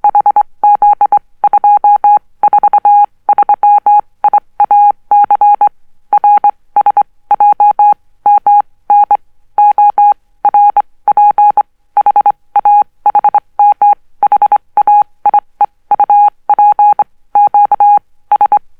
• morse code oscillator - low pitched.wav
morse_code_oscillator_-_low_pitched_NzH.wav